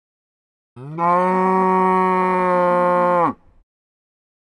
دانلود آهنگ مو مو گاو 2 از افکت صوتی انسان و موجودات زنده
جلوه های صوتی
دانلود صدای مو مو گاو 2 از ساعد نیوز با لینک مستقیم و کیفیت بالا